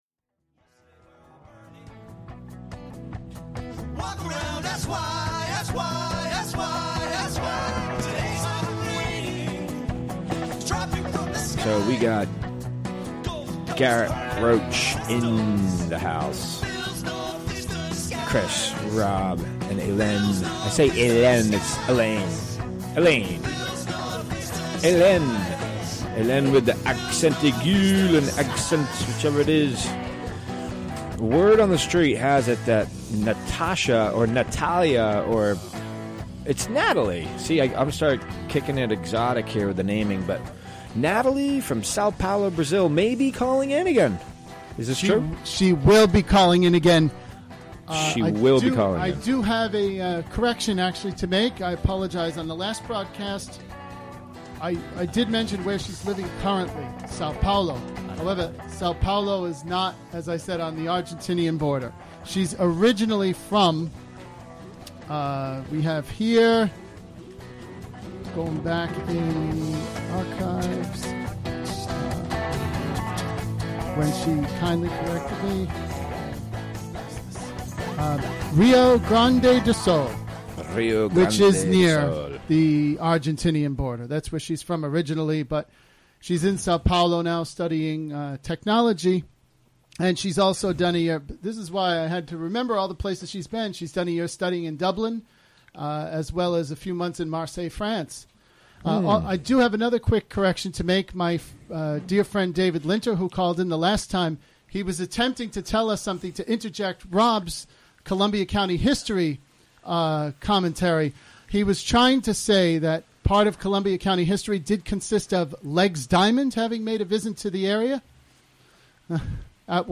Interviewed
Recorded during the WGXC Afternoon Show Monday, June 5, 2017.